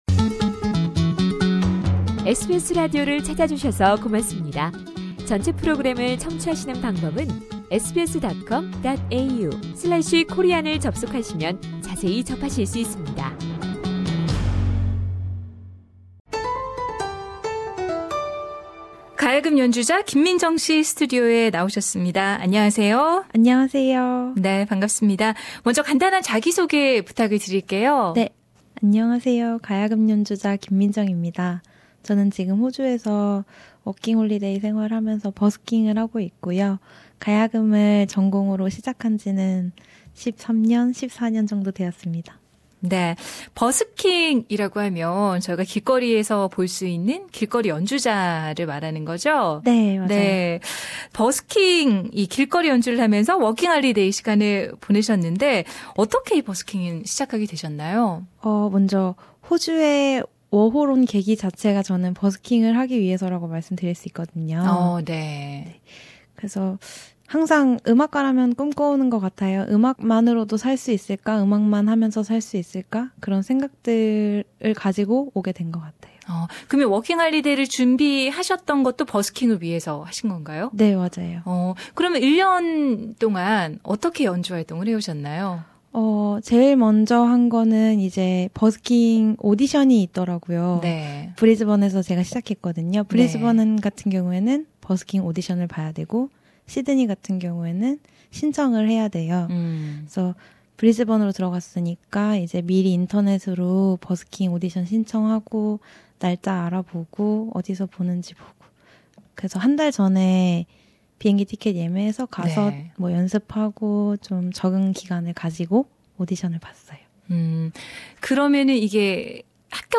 Gayageum